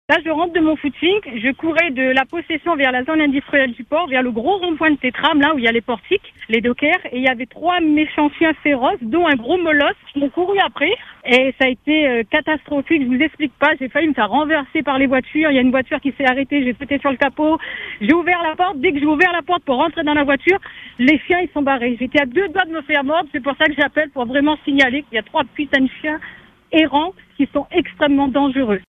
Elle témoigne.